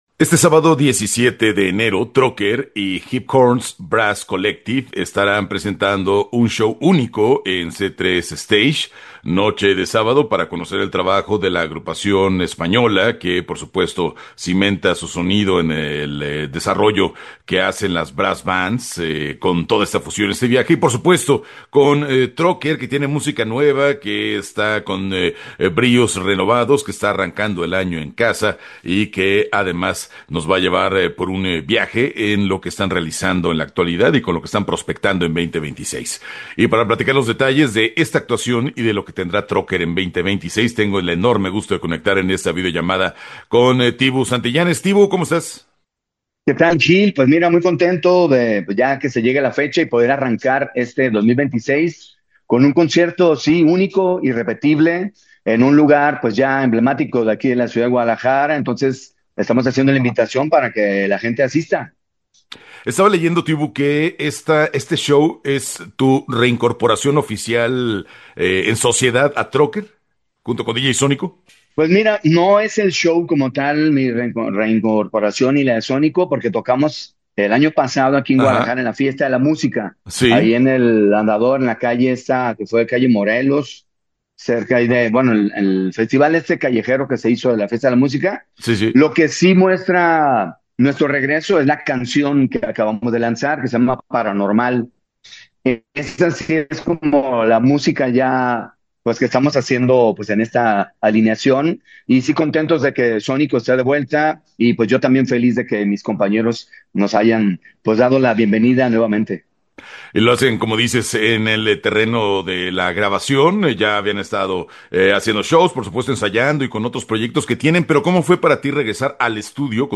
Entrevista-Troker-2026.mp3